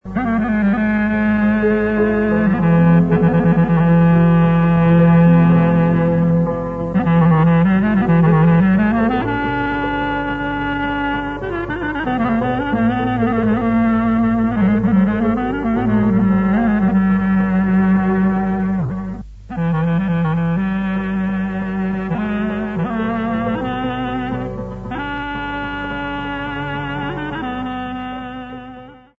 sound track 28, διάρκεια 29'', μουσική (δημοτικό)